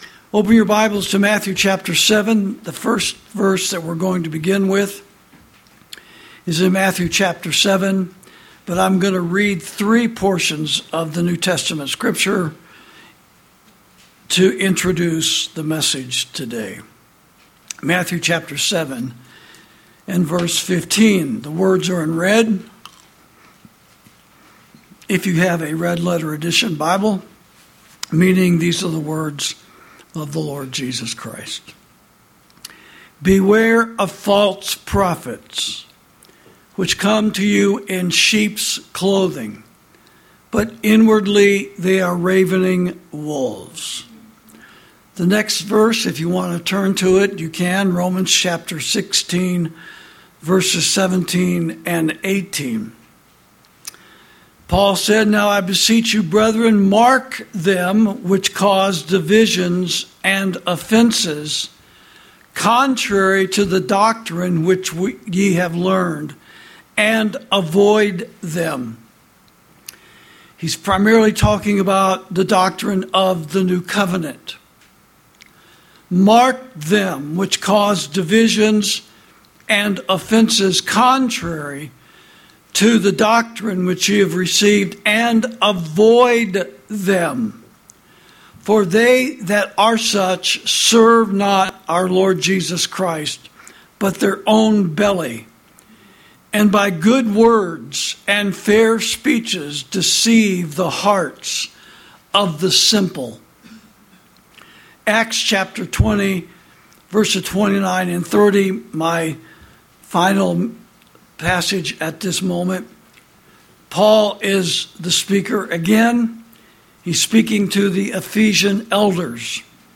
Sermons > Evangelicals Are Still Fighting Old Covenant Wars